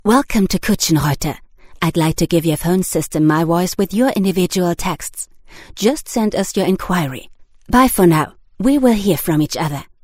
Im Studio werden Ihre Ansagen individuell für Sie produziert – KI-generierte Ansagen oder Texte ‚von der Stange‘ gibt’s bei uns nicht.